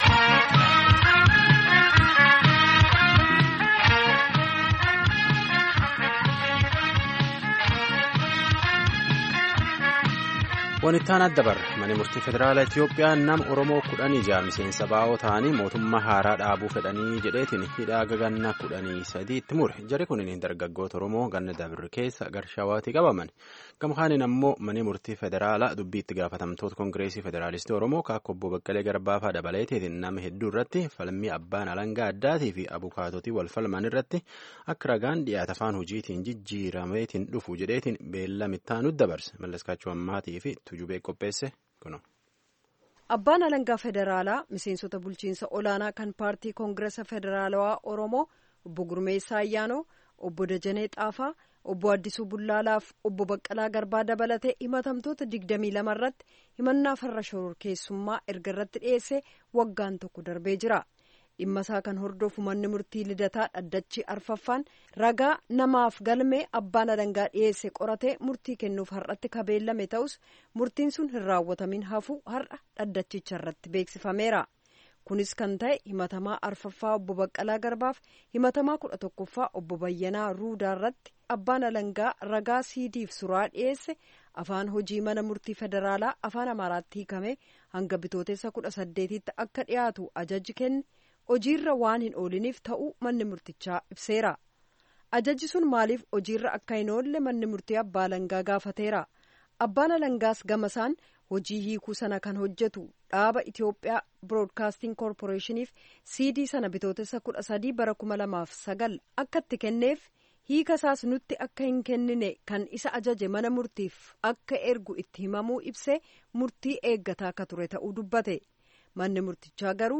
Gabaasa guutuu dhaggeeffadha